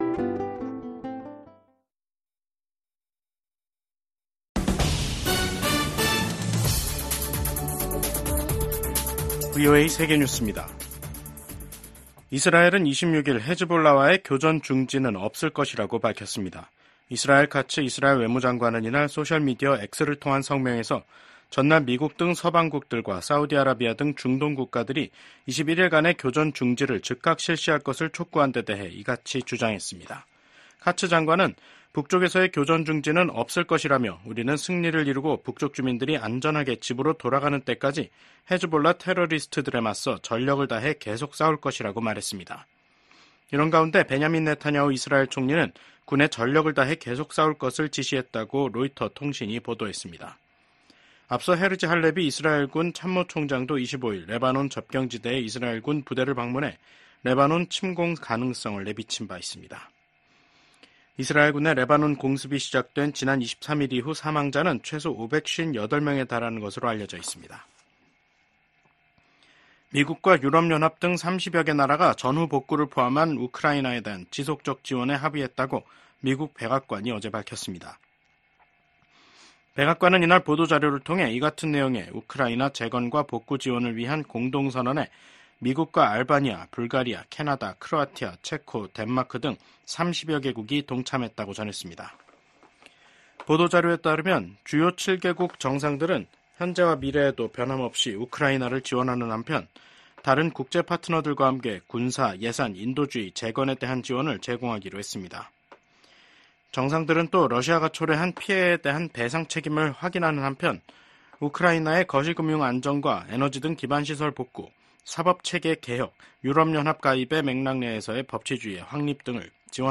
VOA 한국어 간판 뉴스 프로그램 '뉴스 투데이', 2024년 9월 26일 2부 방송입니다. 미한일 3국이 외교장관 회의를 개최하고 ‘정치적 전환기’ 속 변함 없는 공조 의지를 확인했습니다. 미국 정부는 북한의 7차 핵실험이 정치적 결정만 남은 것으로 평가한다고 밝혔습니다.